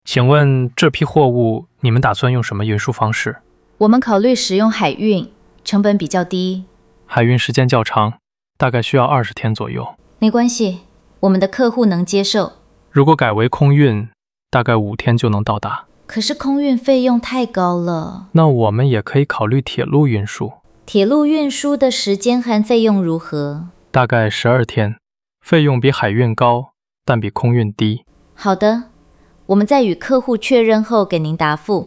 Hội thoại 2: Vận chuyển và logistics